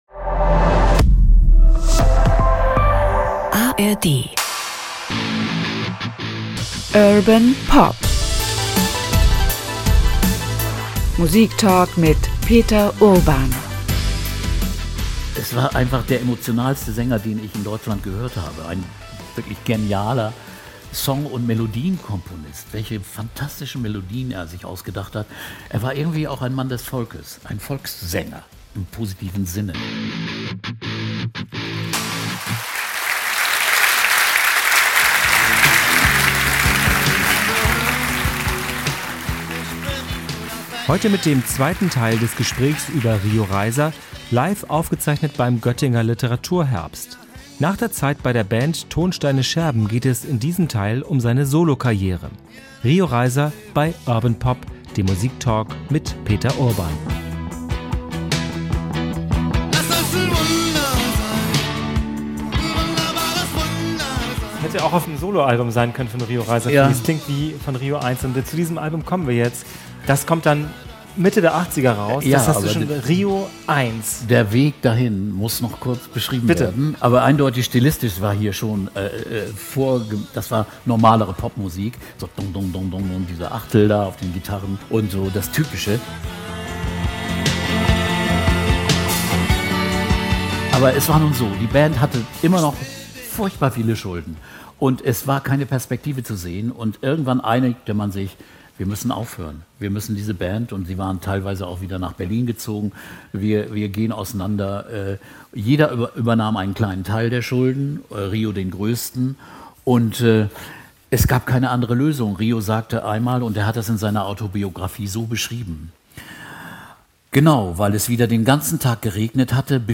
Diese Folge von Urban Pop wurde im Rahmen des Göttinger Literaturherbstes vor Publikum aufgezeichnet.